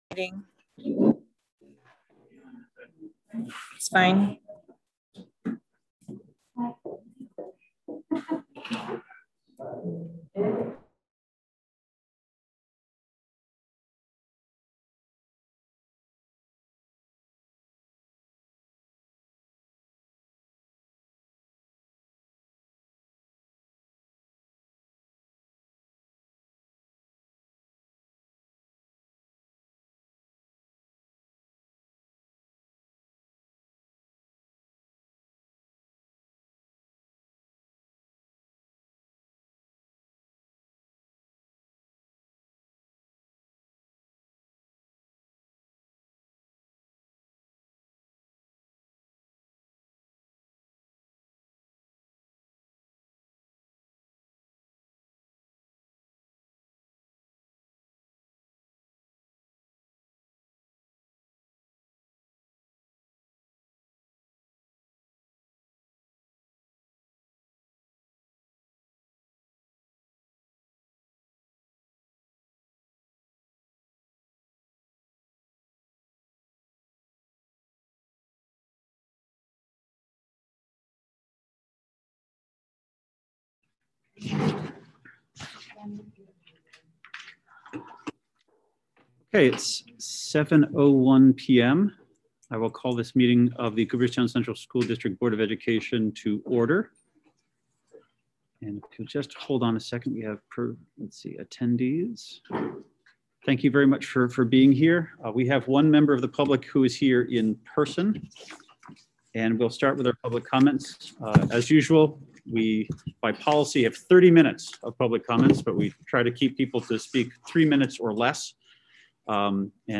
3-17-21_Board_of_Education_audio_only.m4a